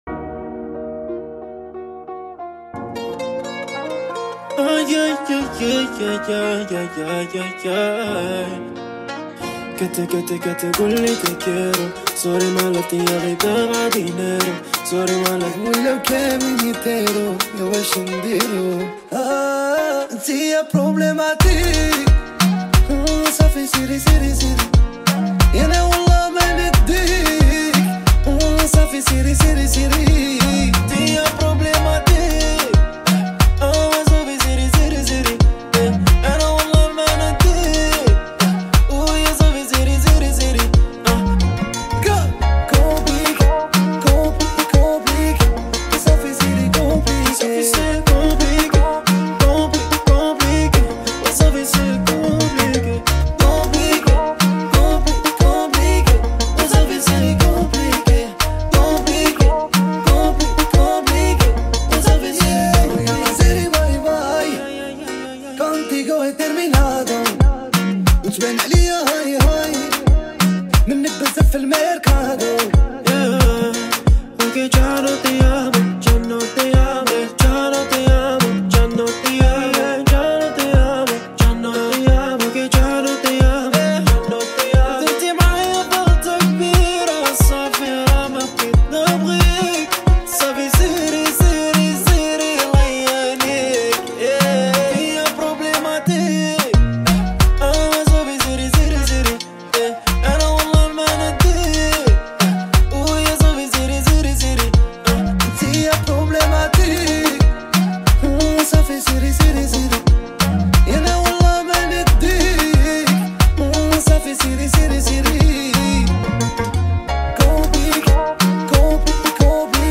اغاني الراي استماع songالنسخة الأصلية MP3